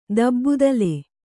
♪ dabbudale